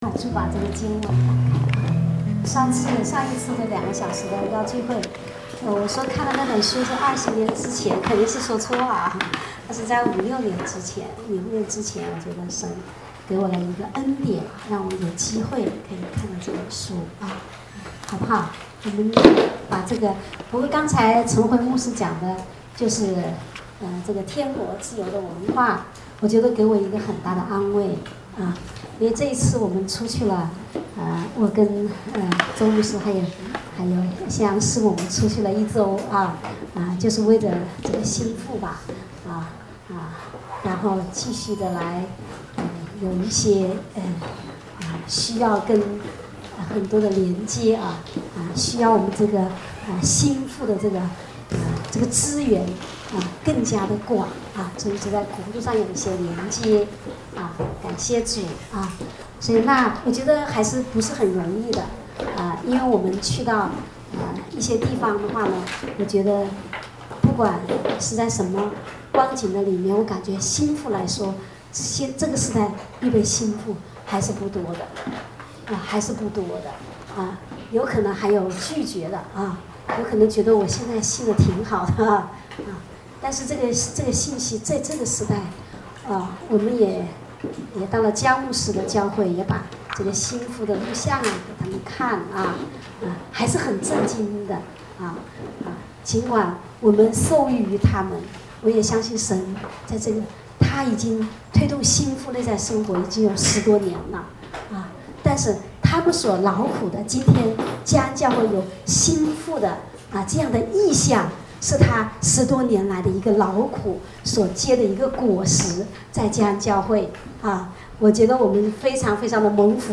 正在播放：--主日恩膏聚会录音（2014-10-12）